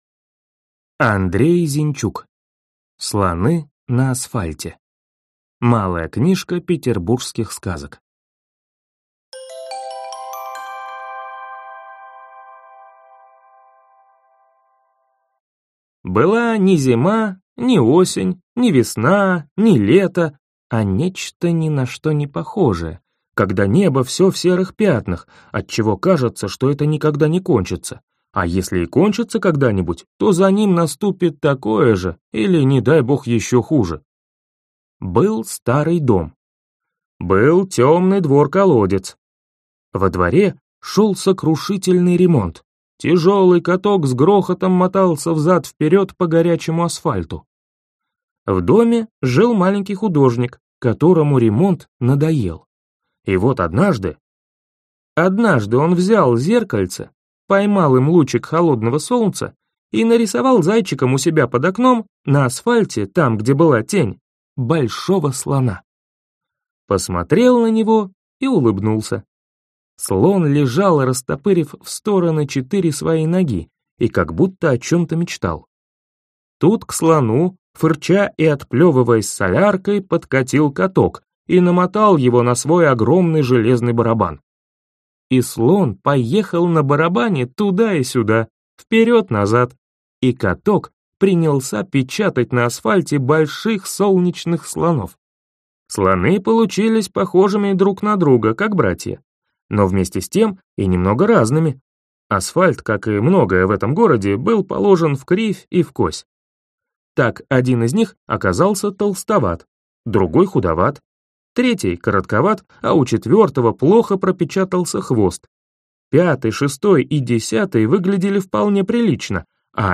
Аудиокнига Слоны на асфальте | Библиотека аудиокниг